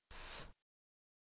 子音/θ/は、舌と歯の間で空気が摩擦することで発音できる「歯摩擦音(は まさつおん)」という音です。
/θ/は無声音の歯摩擦音なので、初めて学ぶ方にとっては思った以上に”優しくてソフト”な「空気のような音」に感じるかもしれません。
子音/θ/のみの発音
子音θのみの発音.mp3